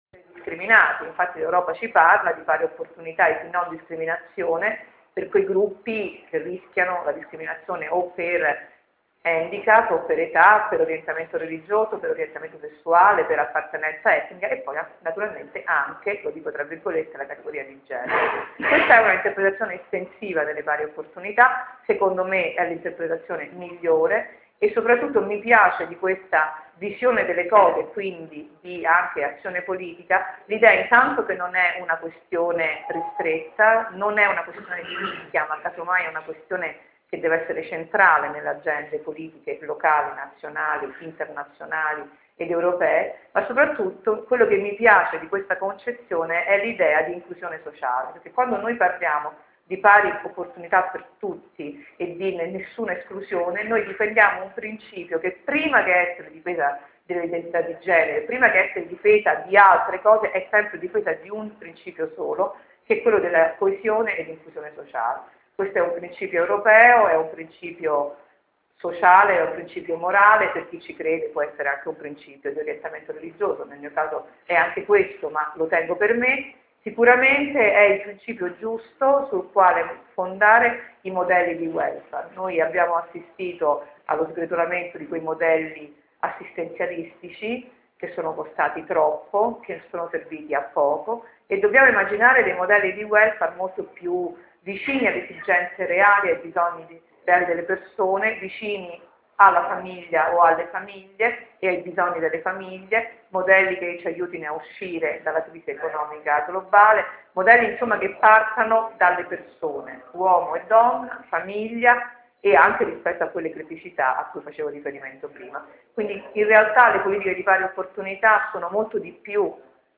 Cura di Vetralla – Intervento